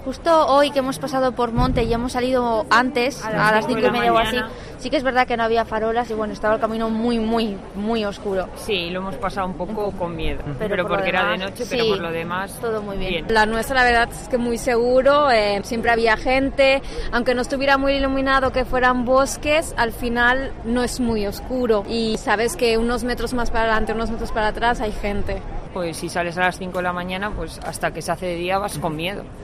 Varias peregrinas opinan sobre la seguridad en el Camino de Santiago